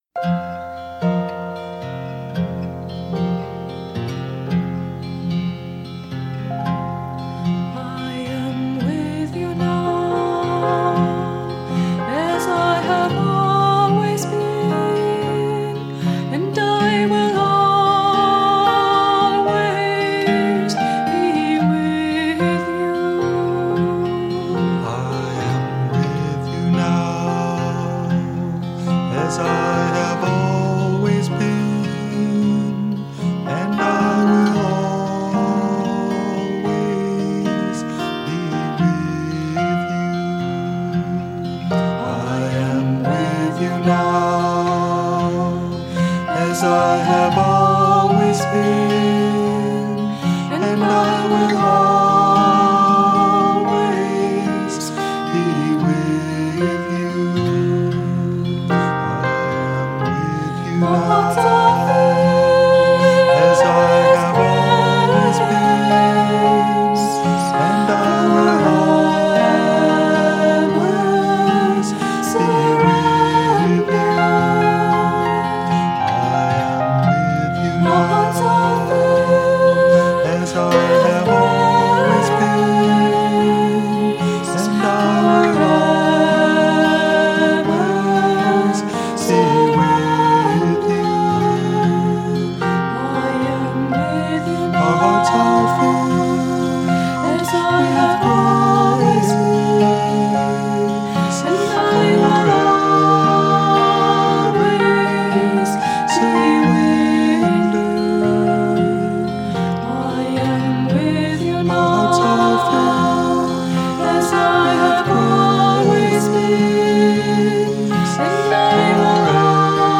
1. Devotional Songs
Minor (Natabhairavi)
8 Beat / Keherwa / Adi
Medium Slow